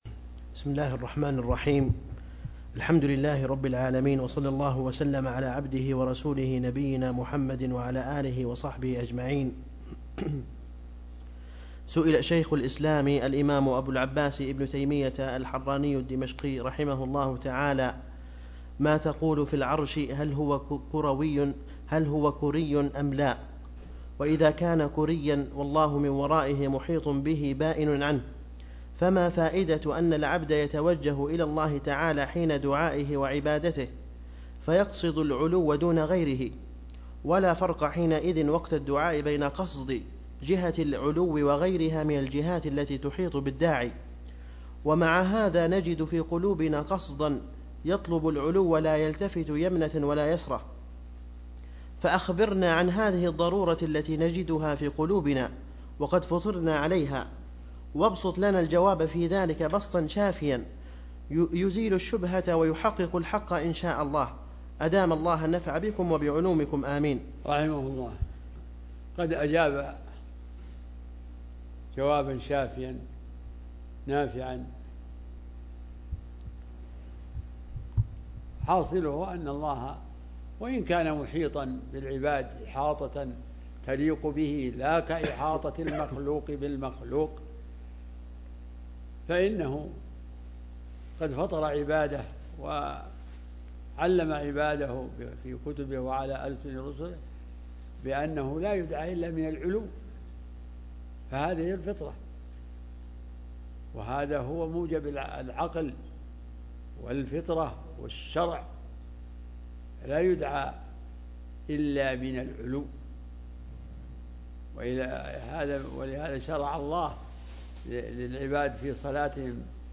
عنوان المادة إعادة للدرس الأول قوله ما تقول في العرش هل هو كري أم لا